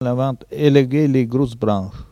Saint-Hilaire-de-Riez
Catégorie Locution